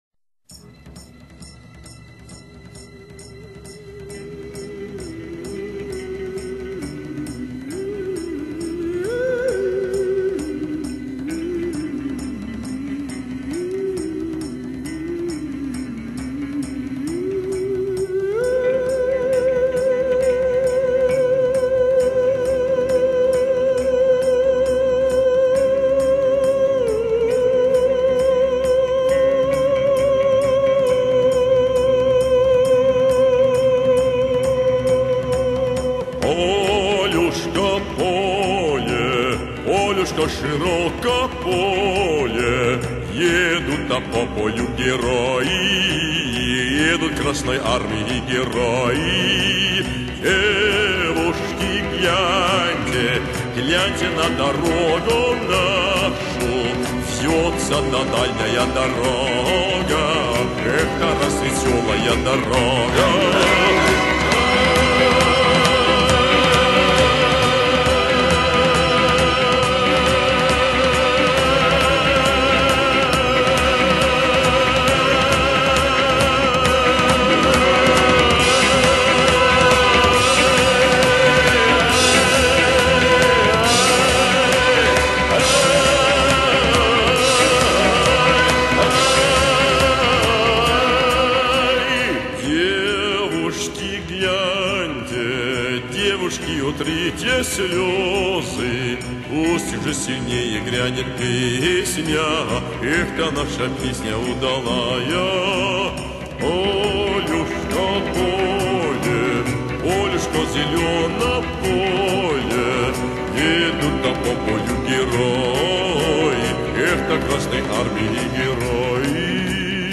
Genre: Folk Russia, Easy Listening